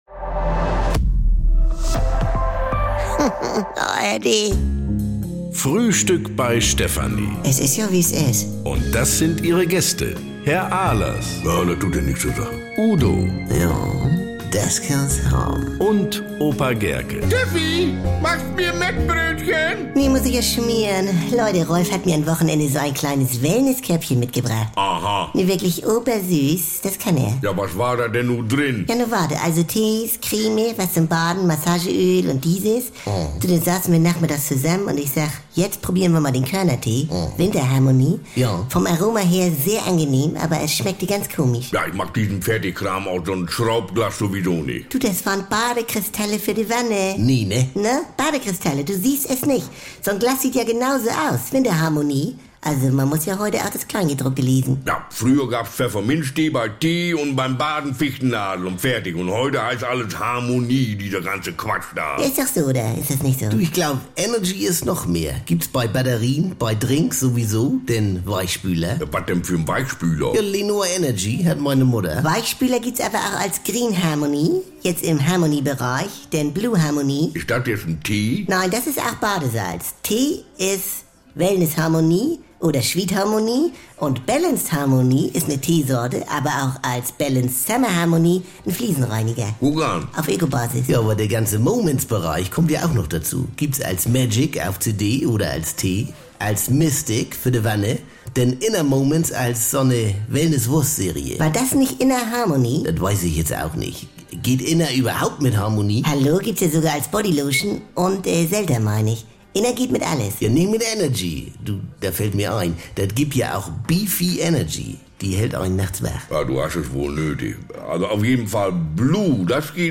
NDR 2 Komödie Frühstück Bei Stefanie
Frisch geschmierte Mettbrötchen, Schnorrer-Tipps, Pyro-Fantasien und brummeliges Gemecker bekommt ihr jeden Tag im Radio oder jederzeit in der ARD Audiothek.